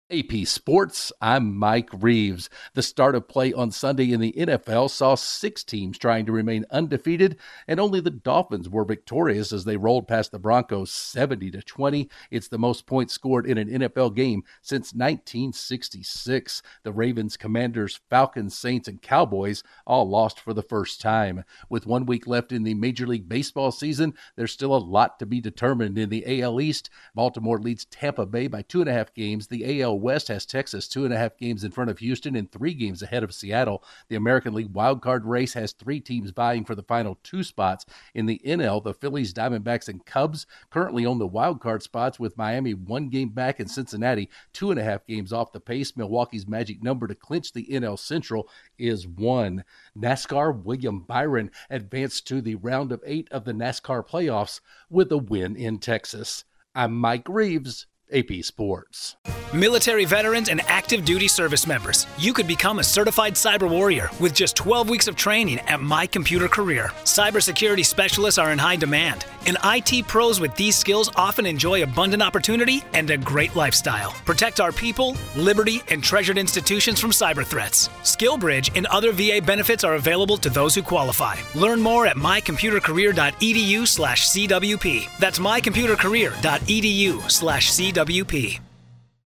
The Dolphins erupt against the Broncos, the Orioles pad their division lead, the NL Central is still up for grabs and William Byron remains in Cup contention. Correspondent